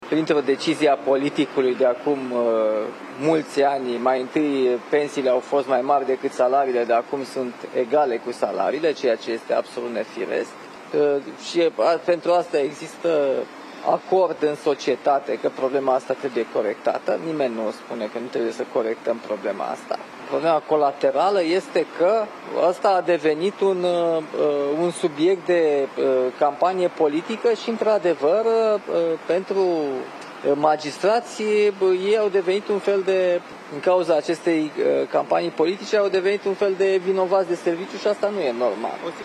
Președintele Nicușor Dan, în urmă cu puțin timp, după ce a depus o coroană de flori la Monumentul lui Corneliu Coposu, la 30 de ani de la moartea acestuia: „Problema colaterală este că ăsta a devenit un subiect de campanie politică și magistrații, din cauza acestei campanii politice au devenit un fel de vinovați de serviciu”